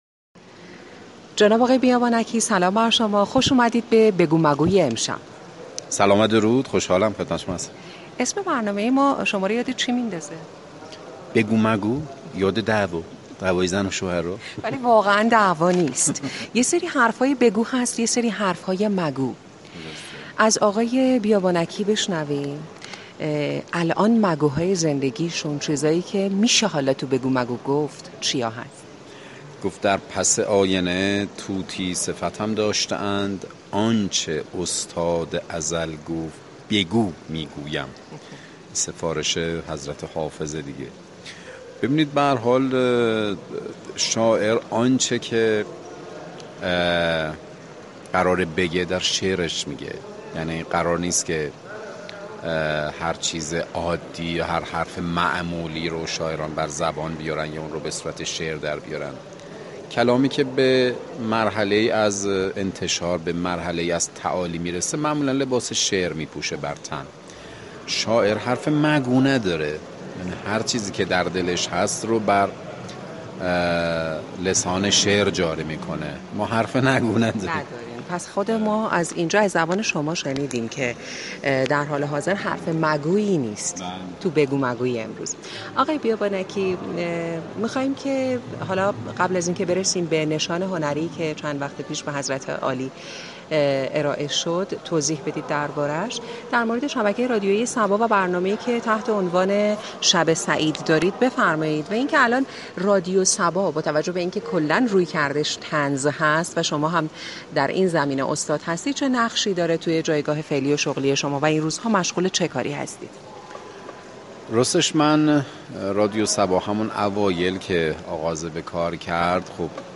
سعید بیابانكی در گفتگو در برنامه بگو مگو خود را به زبان طنز جمع اضداد معرفی كرد.